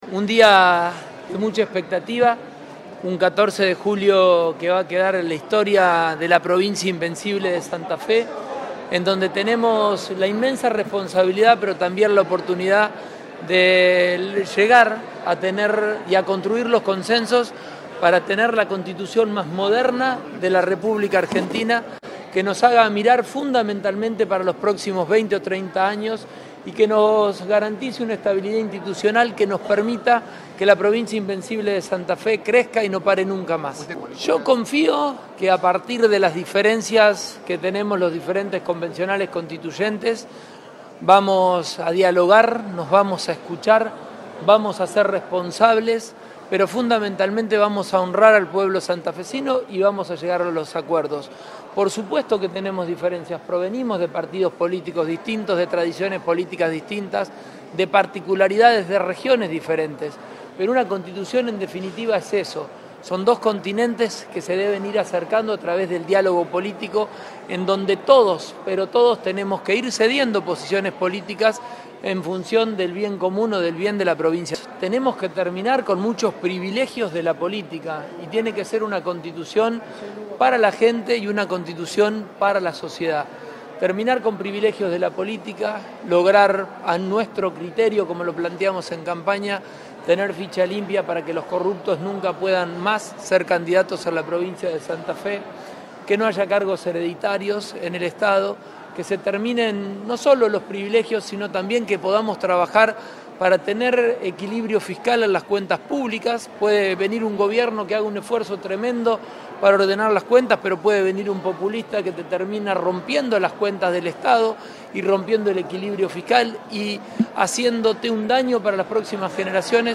Antes de la apertura de la Convención Reformadora de la Constitución de Santa Fe, el gobernador Maximiliano Pullaro -también uno de los 69 convencionales- subrayó que este lunes 14 de julio “es una jornada que quedará en la historia de la provincia invencible, porque tenemos la responsabilidad y la oportunidad de acordar una Carta Magna que mire a los próximos 20 o 30 años y garantice estabilidad institucional para que Santa Fe crezca y no se detenga nunca más”.
Declaraciones del Gobernador Pullaro